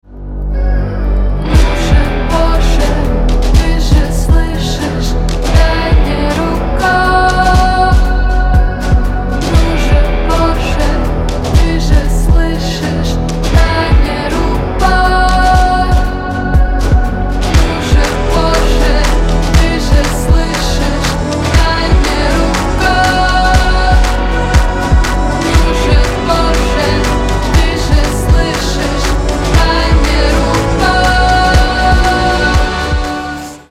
• Качество: 320, Stereo
спокойные
красивый женский голос